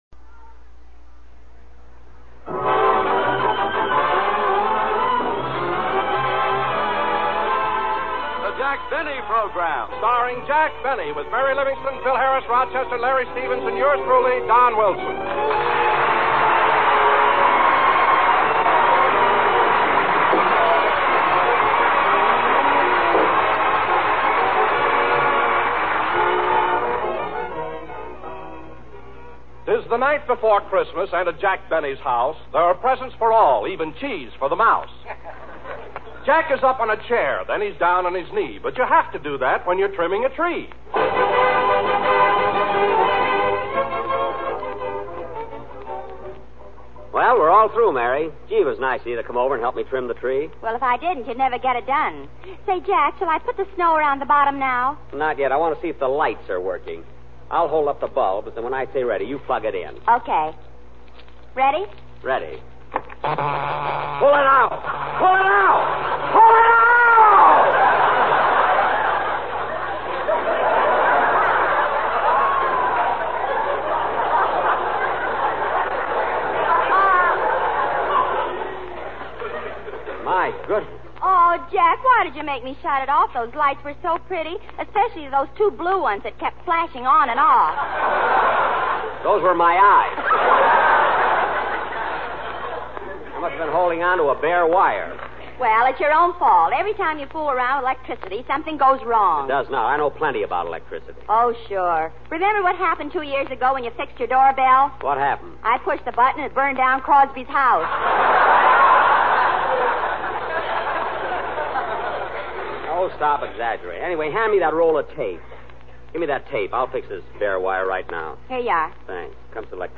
The hyped-up kids in the studio audience certainly seemed to enjoy themselves.